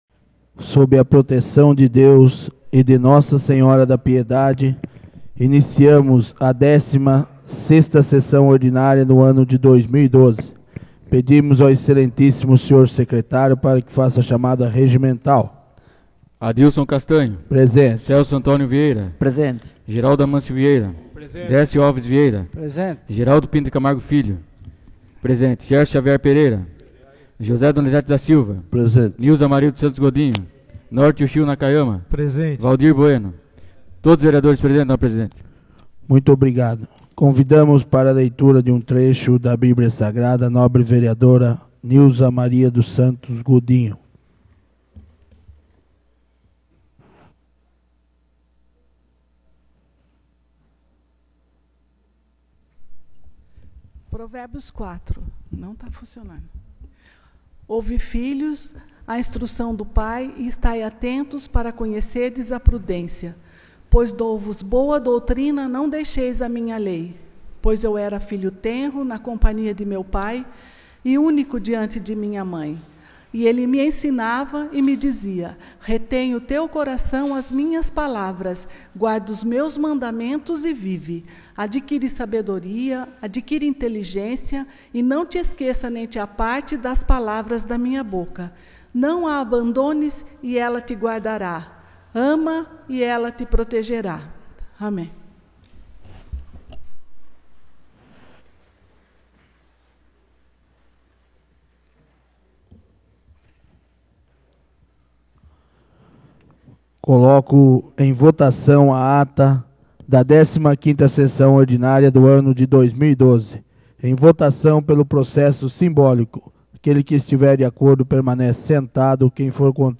16ª Sessão Ordinária de 2012 — Câmara Municipal de Piedade